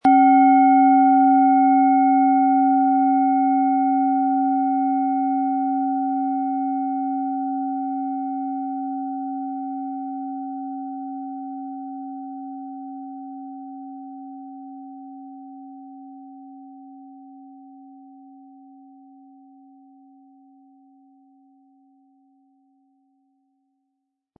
Wir haben versucht den Ton so authentisch wie machbar hörbar zu machen, damit Sie hören können, wie die Klangschale bei Ihnen klingen wird.
Durch die traditionsreiche Fertigung hat die Schale vielmehr diesen kraftvollen Ton und das tiefe, innere Berühren der traditionellen Handarbeit
PlanetentöneBiorythmus Körper & Tageston
MaterialBronze